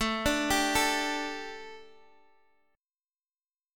Listen to A7sus4 strummed